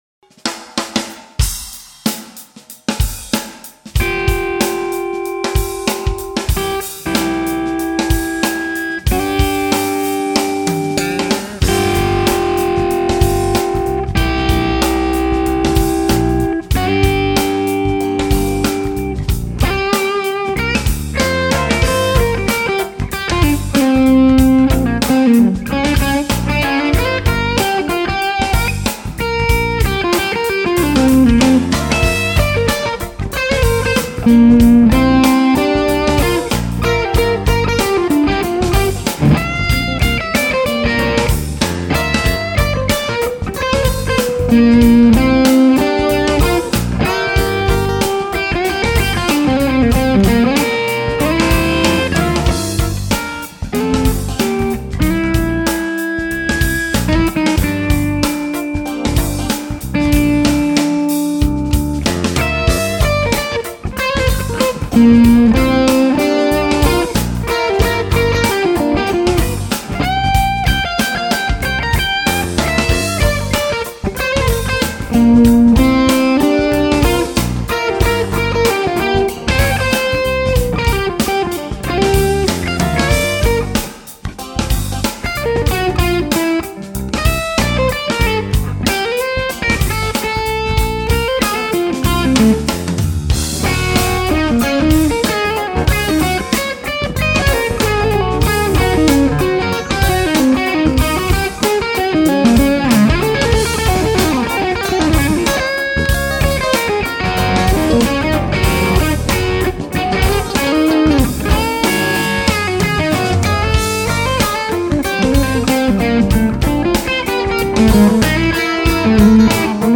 TAD short bottles and a larger OD1 cap